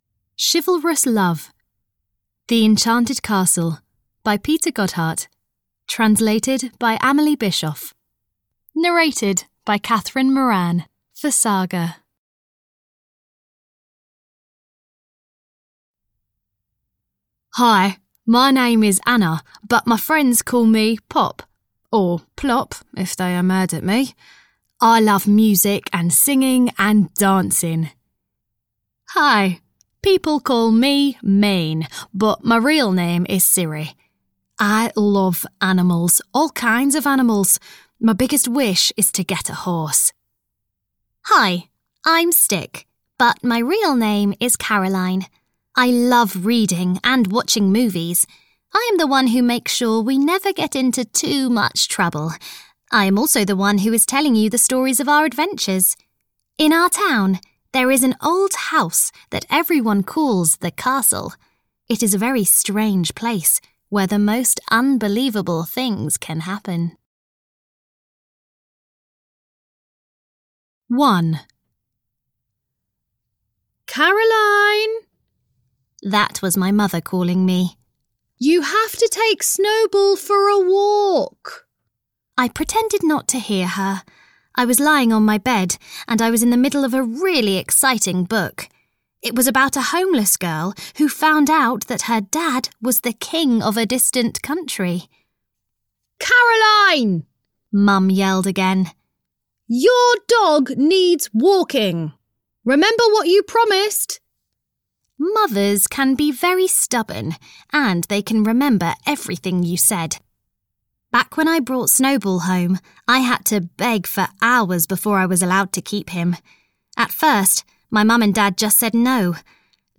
Audio knihaThe Enchanted Castle 2 - Chivalrous Love (EN)
Ukázka z knihy